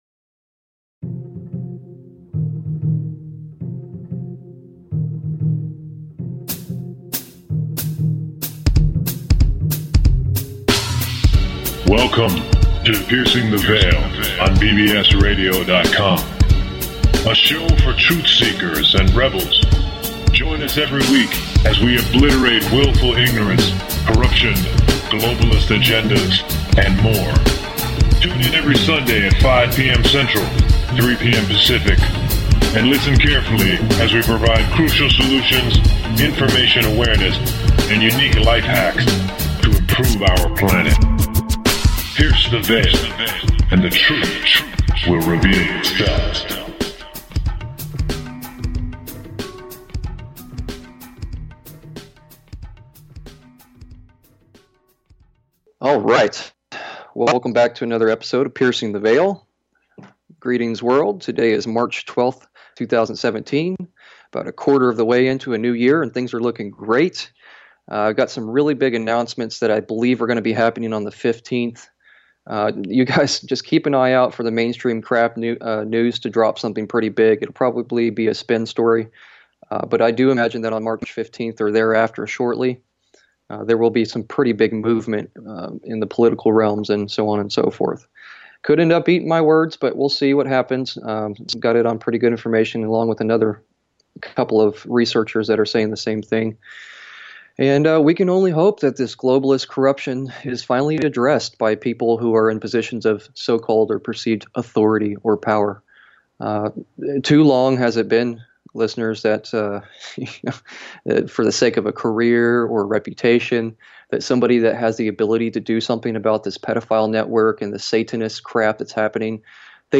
Show 49- Listener & Past Guest Call In , March 12, 2017